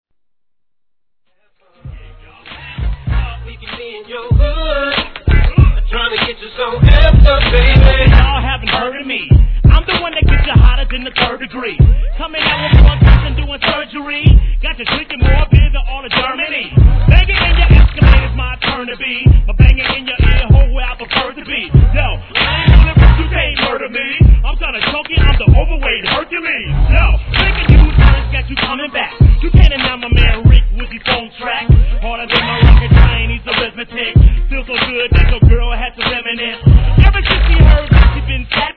G-RAP/WEST COAST/SOUTH
重量感たっぷりのクラップオンを効かせたトラックに